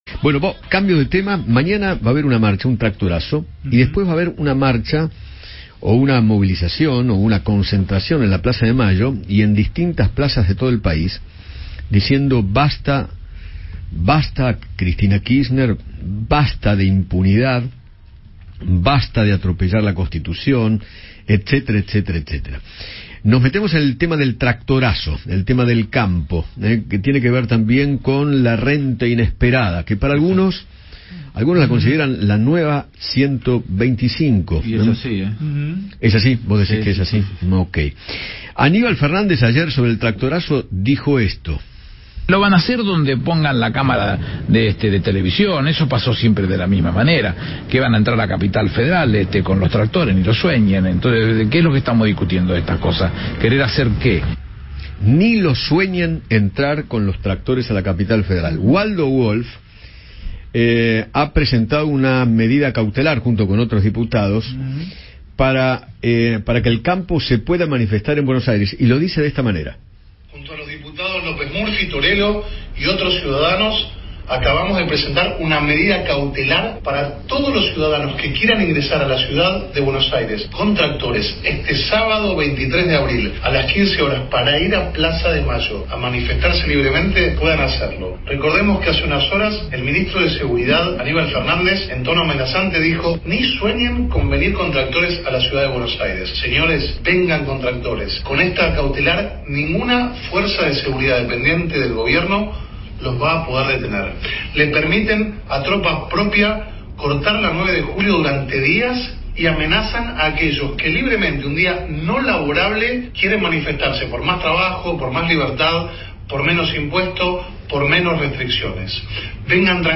Luis Miguel Etchevehere, ex presidente de la Sociedad Rural Argentina, conversó con Eduardo Feinmann sobre la decisión del campo de realizar un tractorazo en la Plaza de Mayo contra el Gobierno.